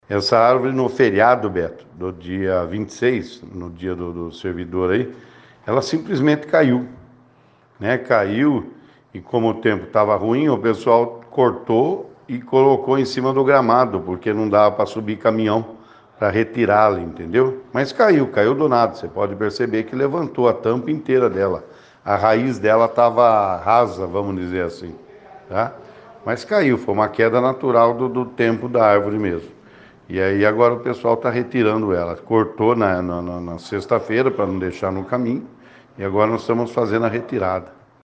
Nossa reportagem esteve falando com o secretário Carlos Cerri Junior, que nos informou que a árvore já estava bem velha e sua raiz estava rasa, por conta disso houve uma queda natural da mesma.